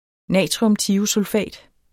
Udtale [ -ˌtiosulˌfæˀd ]